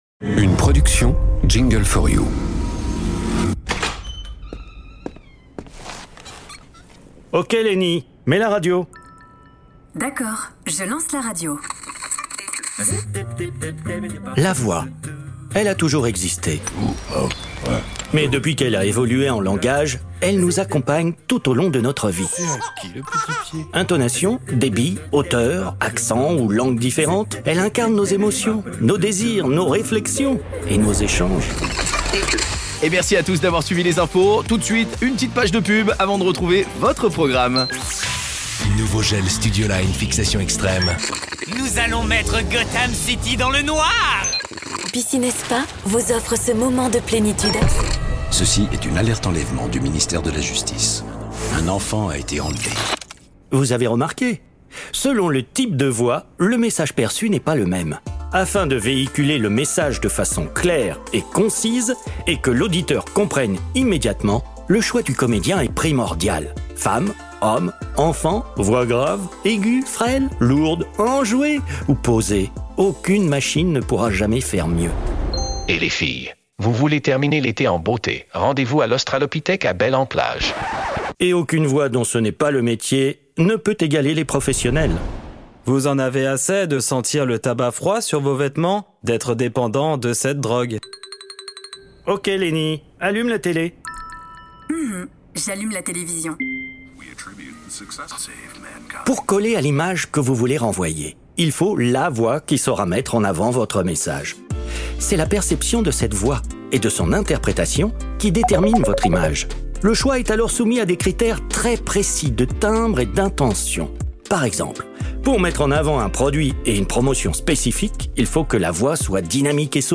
Afin de promouvoir la qualité et l’importance de la voix et de valoriser le travail des comédiens professionnels, le studio vient de publier une démo de quelques minutes pour illustrer le pouvoir de la voix.